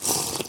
drink.ogg